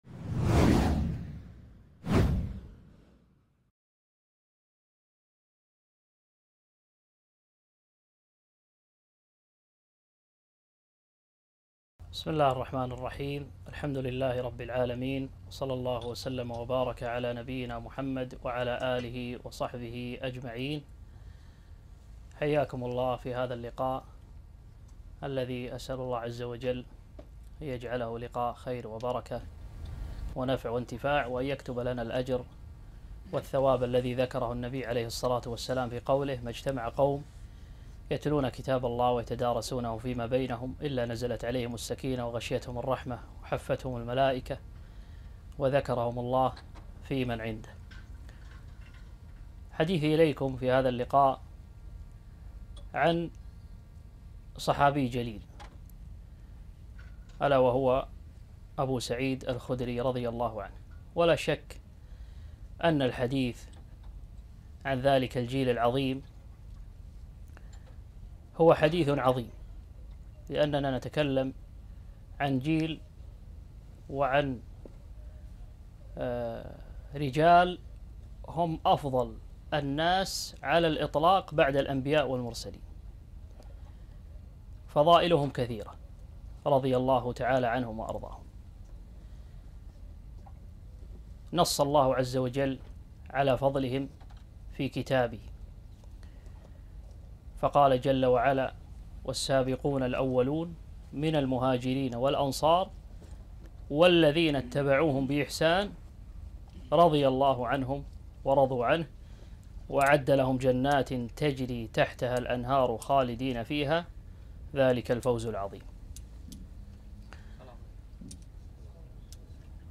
محاضرة - سيرة الصحابي ابو سعيد الخدري